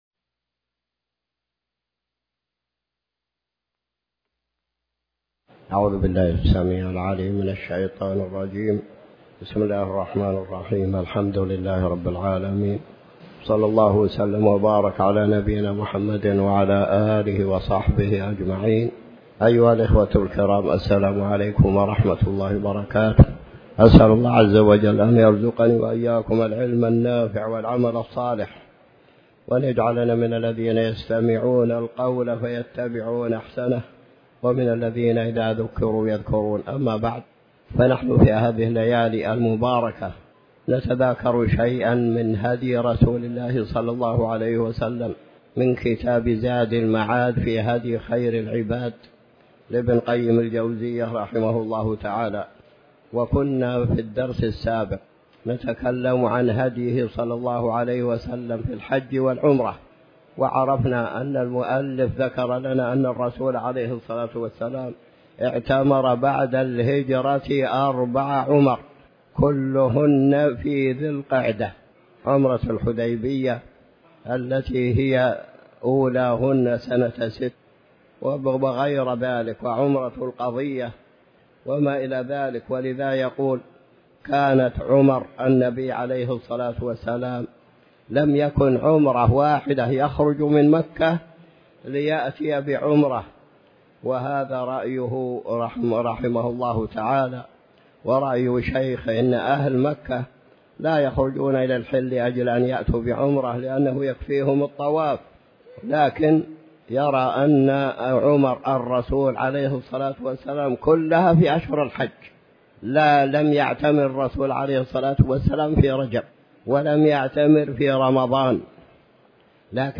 تاريخ النشر ٢٢ محرم ١٤٤٠ هـ المكان: المسجد الحرام الشيخ